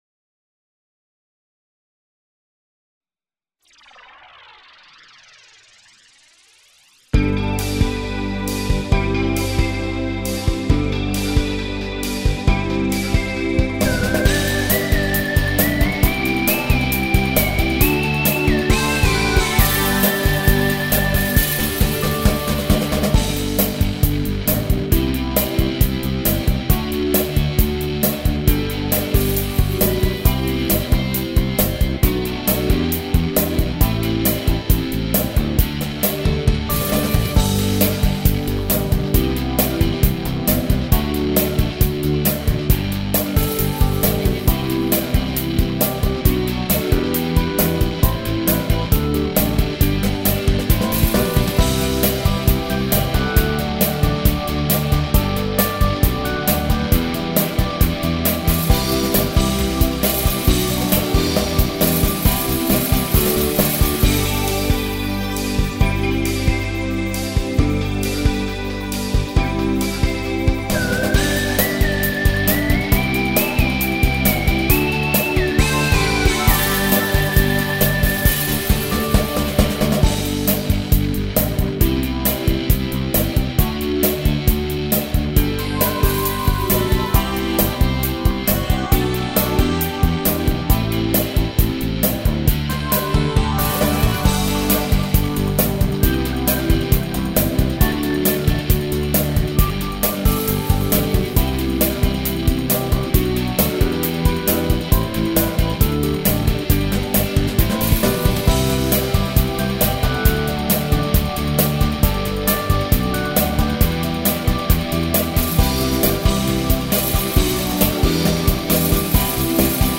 ดนตรี